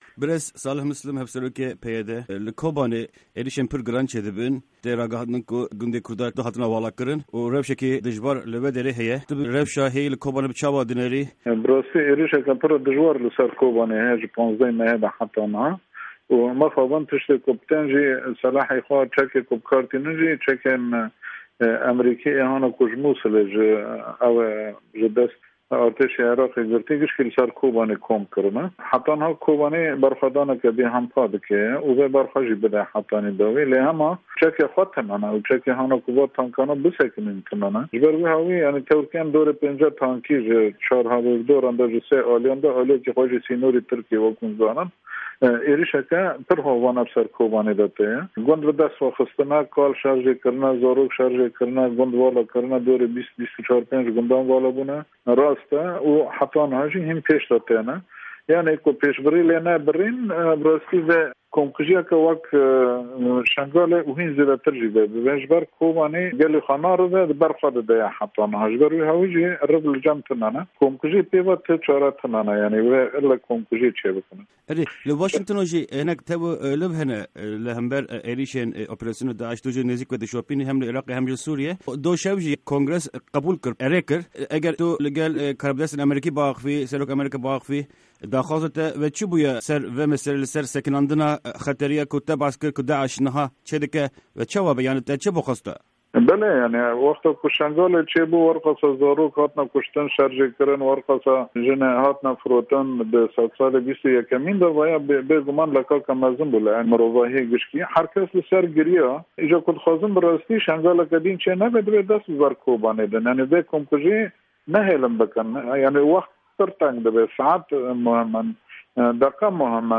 Hevserokê PYD Salih Mislim di hevpeyvîna Dengê Amerîka de êrîşên giran yên DAÎŞ li ser Kobanê dinirxîne û banga alîkarî ji civata navnetewî dike .